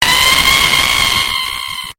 noo noo jumpscare Download for Android
noo-noo-jumpscare.mp3